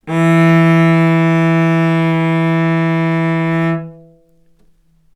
vc-E3-ff.AIF